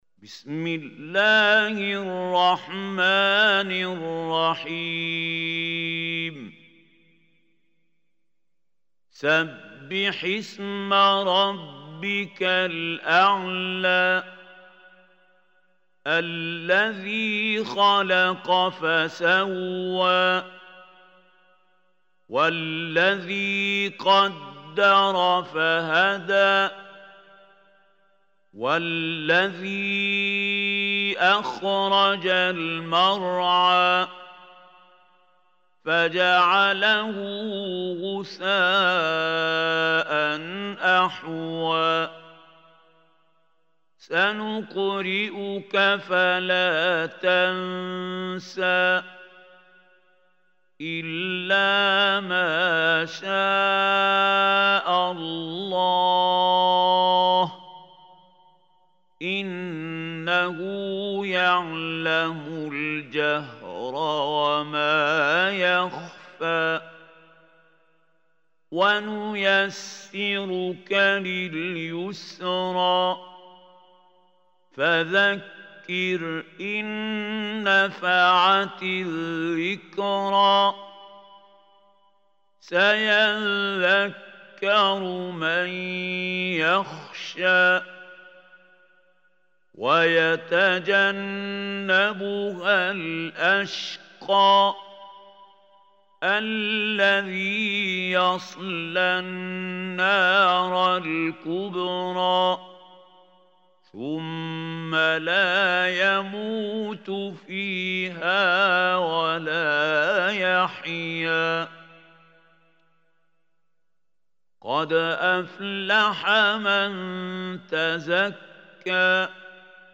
Surah Al Ala MP3 Recitation by Mahmoud Hussary
Surah Al Ala is 87 surah of Holy Quran. Listen or play online mp3 tilawat / recitation in Arabic in the beautiful voice of Sheikh Mahmoud Khalil Al Hussary.